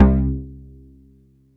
JUNOLOW C2-R.wav